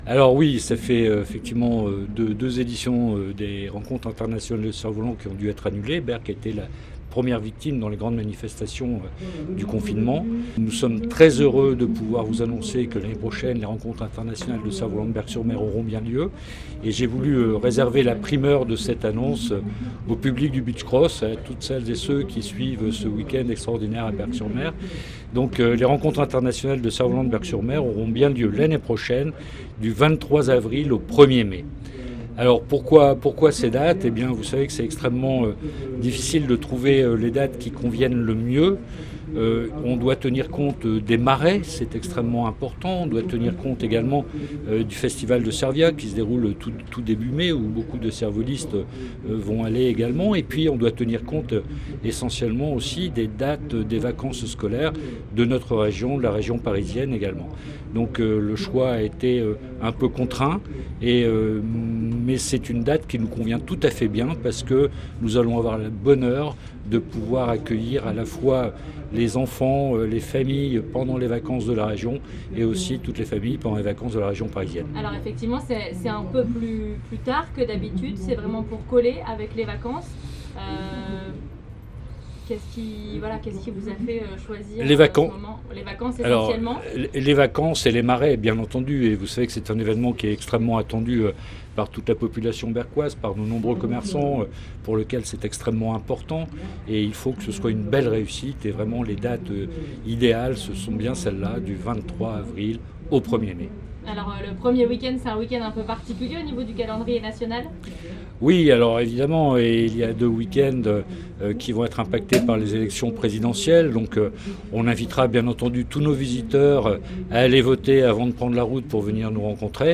Après deux ans d'absence à cause de la crise sanitaire, les Rencontres Internationales de Cerfs-Volants de Berck seront de retour du samedi 23 avril au dimanche 1er mai 2022, Bruno Cousein, le maire de Berck l'a annoncé en direct ce dimanche sur RADIO6.